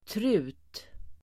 Uttal: [tru:t]